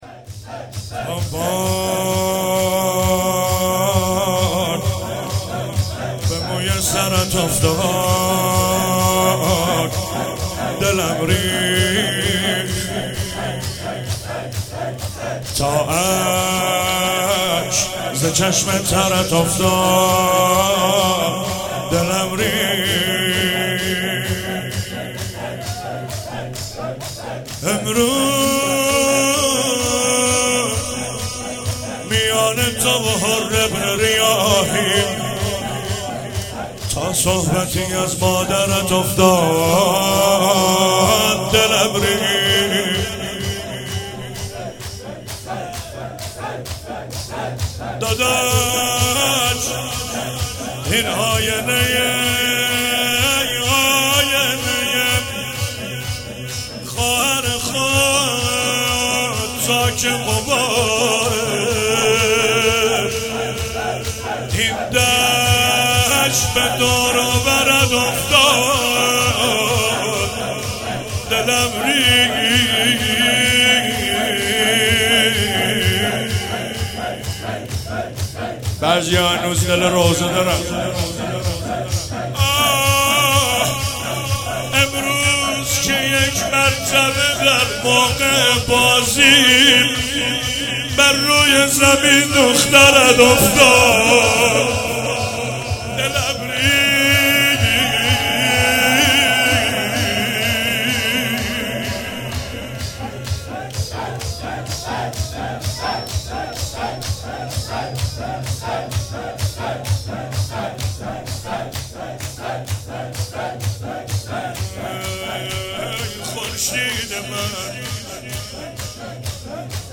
شب سوم رمضان 95، حاح محمدرضا طاهری
03 heiat alamdar mashhad.mp3